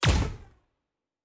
pgs/Assets/Audio/Sci-Fi Sounds/Weapons/Weapon 01 Shoot 3.wav at master
Weapon 01 Shoot 3.wav